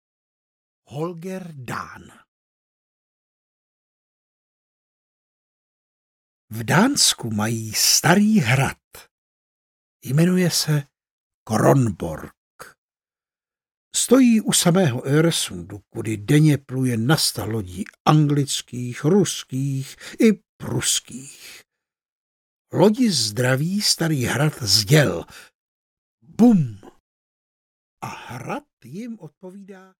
Holger Dán audiokniha
Ukázka z knihy